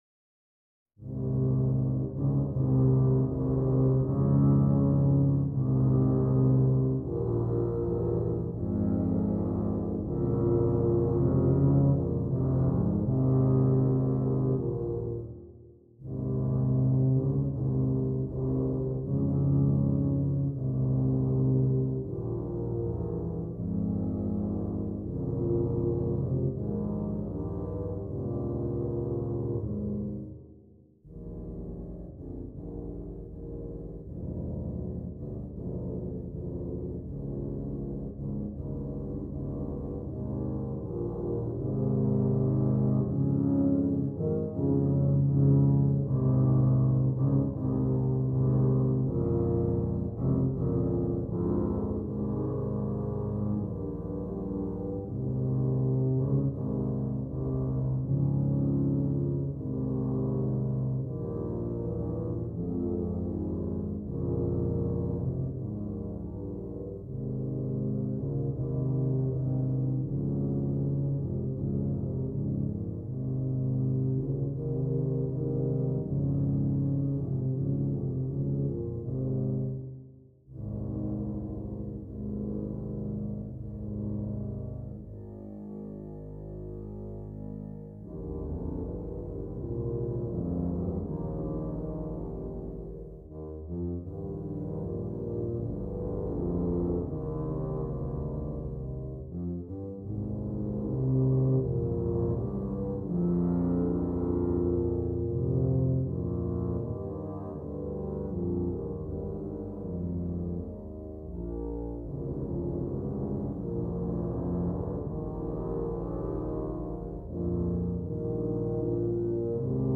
Brass
4 Tubas